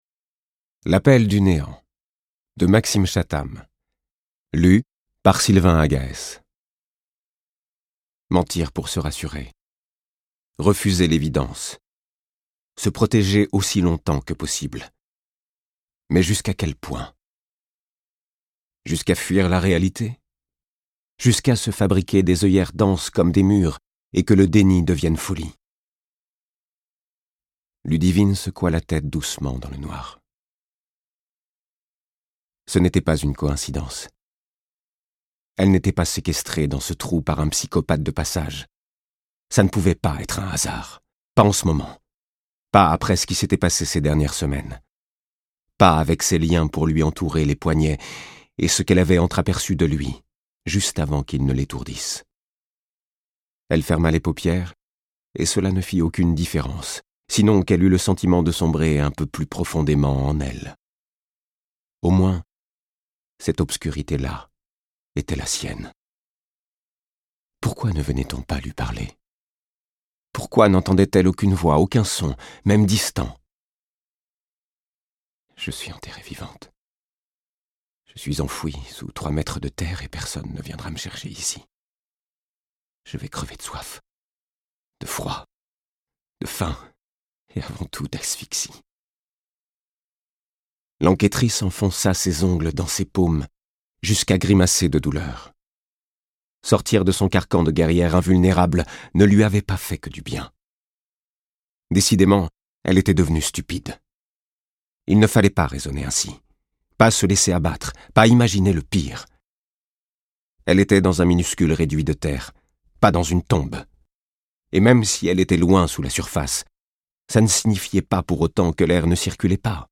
Il sait rendre avec pertinence la noirceur des faits, les émotions des protagonistes.